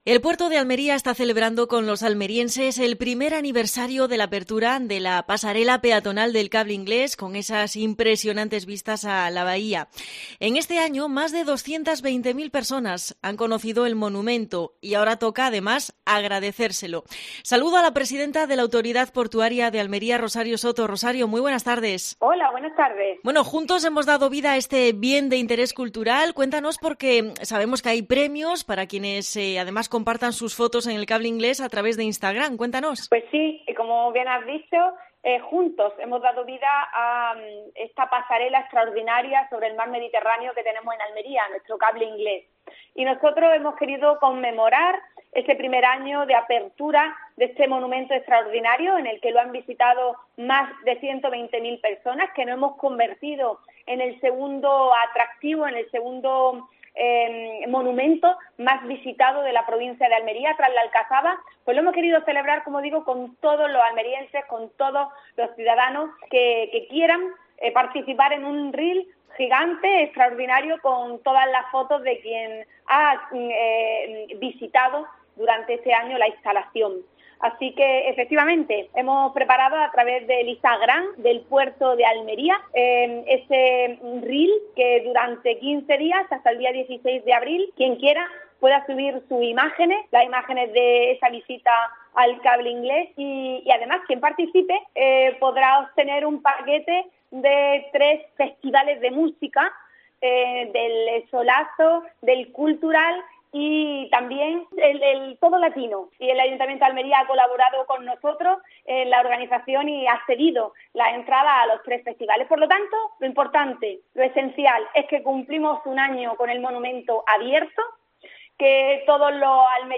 Entrevista a Rosario Soto, presidenta de la Autoridad Portuaria de Almería